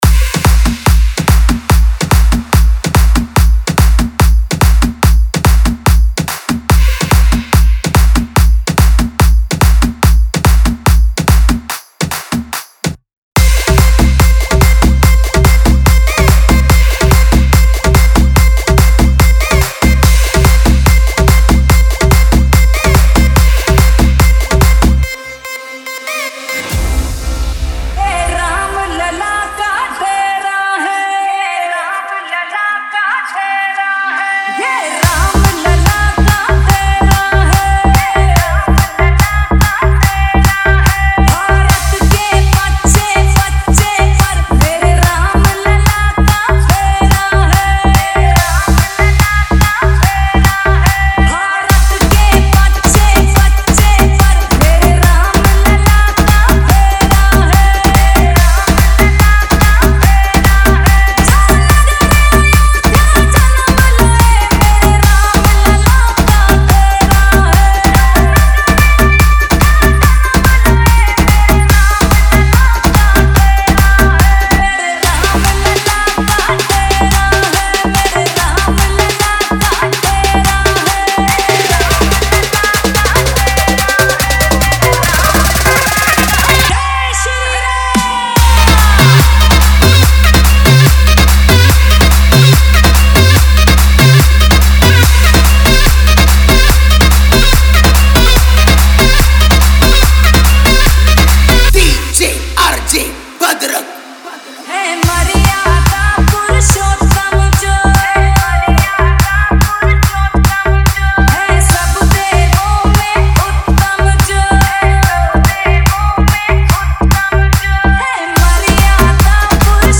Category : Ram Navami Special Dj